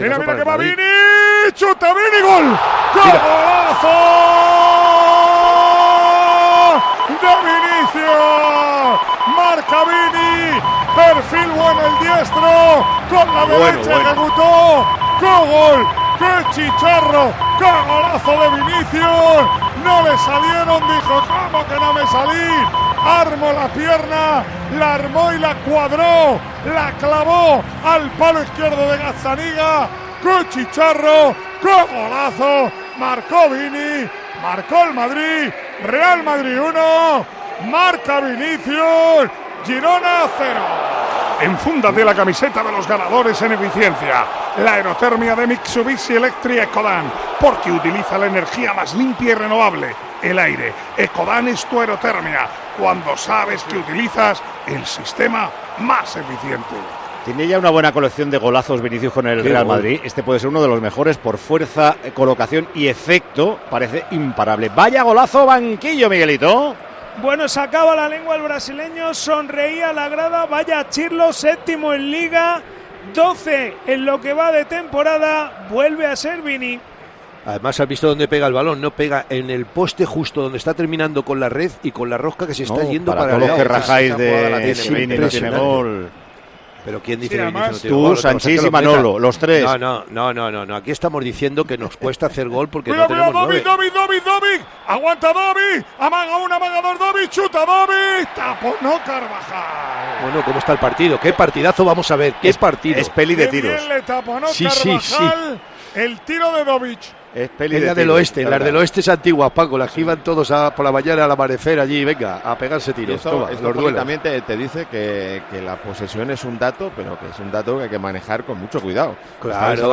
Así vivimos en Tiempo de Juego la retransmisión del Real Madrid - Girona